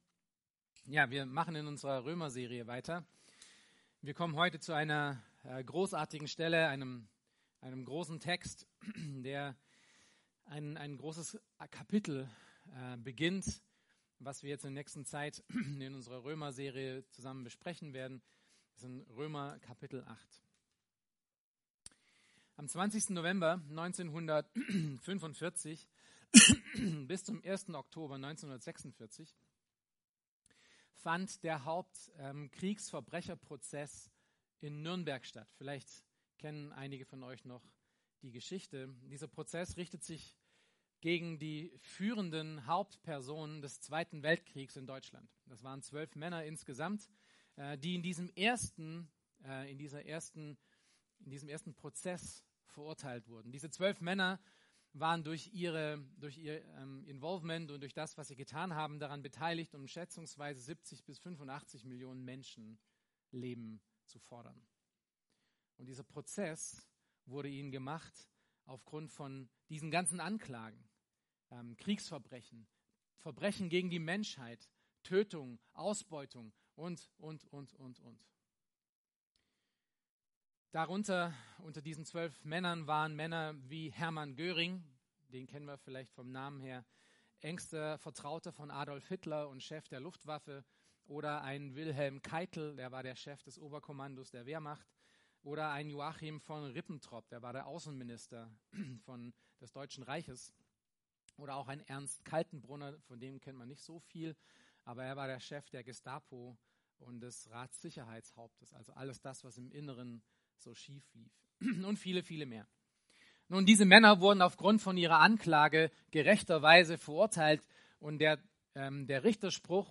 Predigten der Eckstein Gemeinde
Schön, dass Du unsere Predigt hörst.